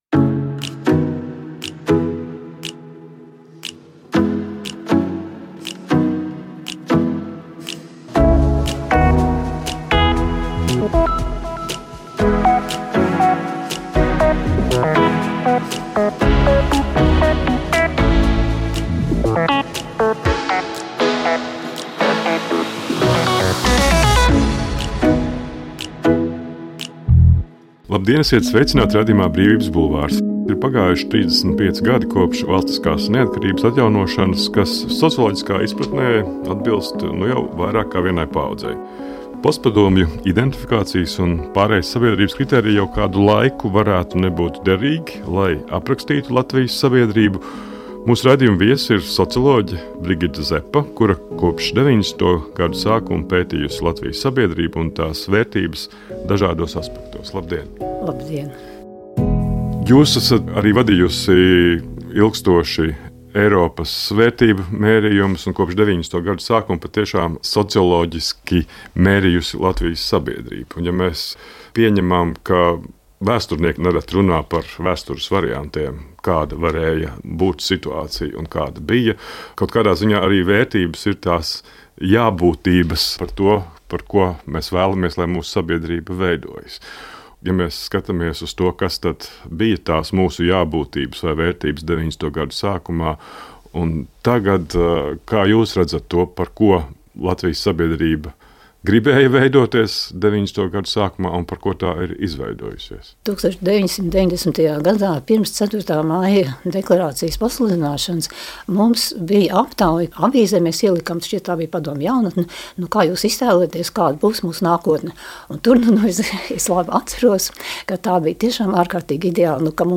Raidījums “Brīvības bulvāris” reizi nedēļā piedāvās tikšanās un sarunas ar cilvēkiem, kuru domas un idejas liek varbūt mums pašiem kļūt gudrākiem.